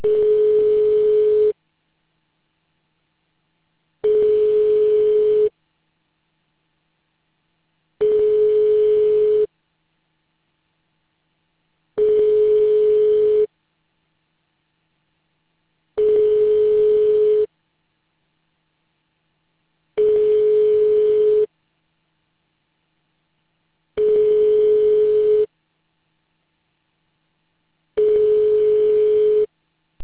DOWNLOAD Standard 16bit mulaw PCM PSTN Ringback tone